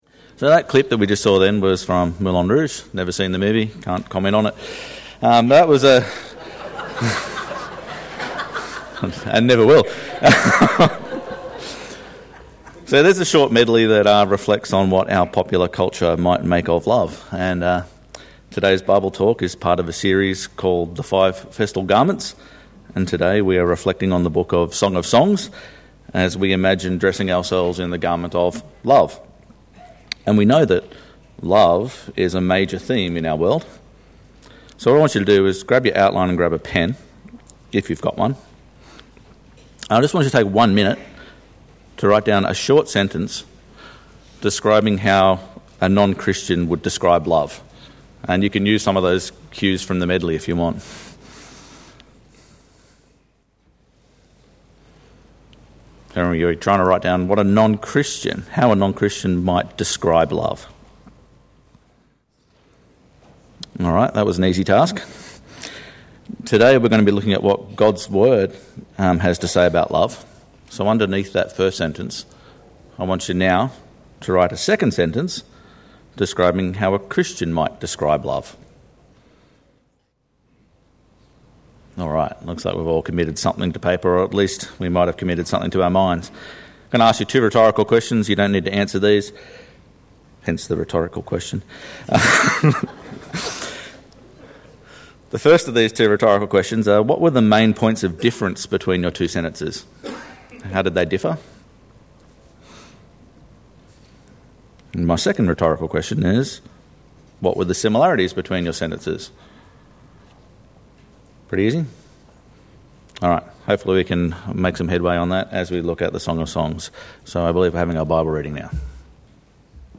Sermon – A Festal Garment (Song of Songs)